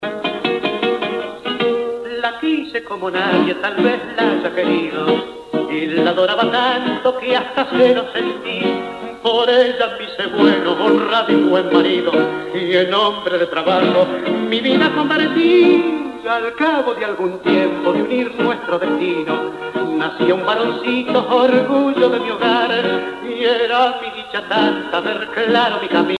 danse : tango (Argentine, Uruguay)
Pièce musicale éditée